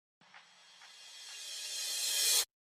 Catégorie SMS